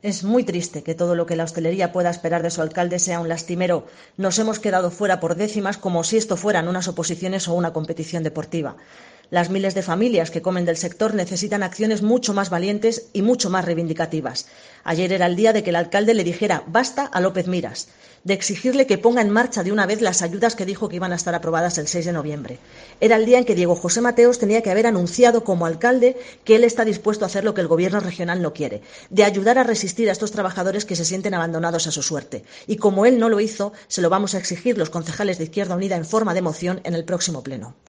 Gloria Martín, edil de IU Verdes en el Ayto Lorca